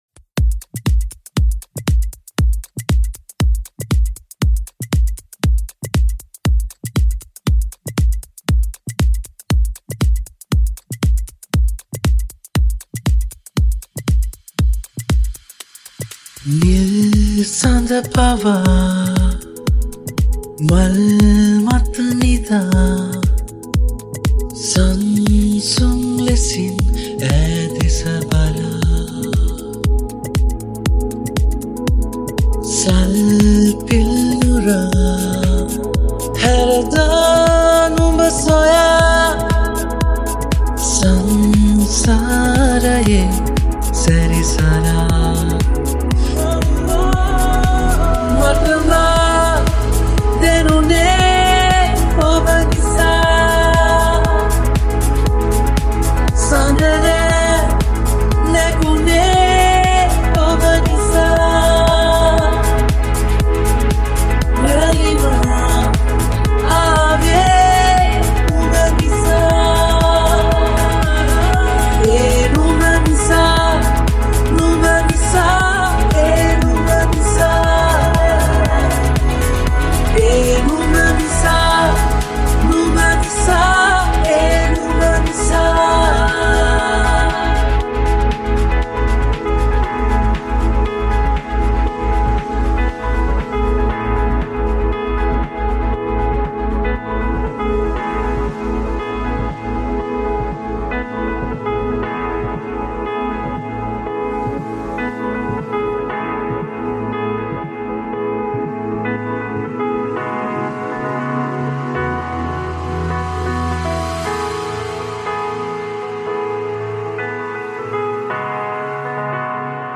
Progressive House Remake